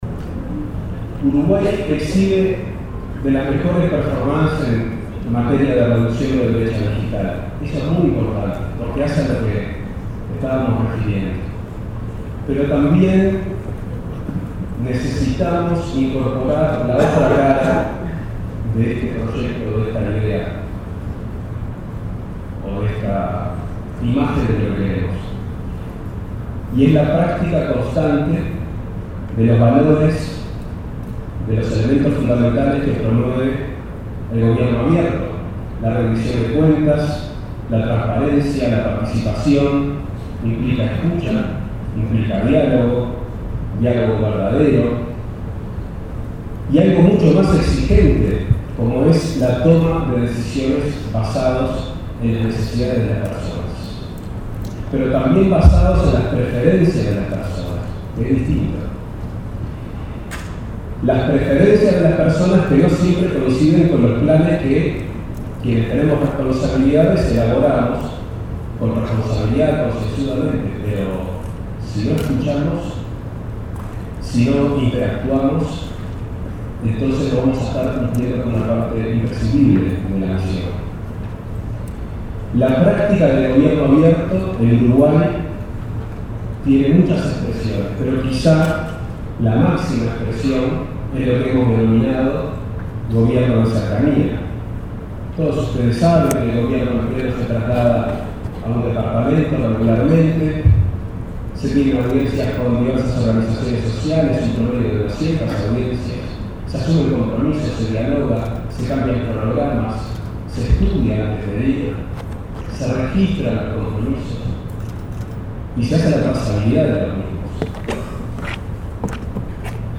El prosecretario de Presidencia, Juan Andrés Roballo, destacó la importancia de acercar el Estado a la gente y, en ese marco, valoró la práctica del gobierno de cercanía, con los Consejos de Ministros realizados en todo el país, en los que se interactuó con la gente. Durante un encuentro de Agesic sobre transformación digital, realizado este jueves en la Torre Ejecutiva, destacó la reducción de la brecha digital en Uruguay.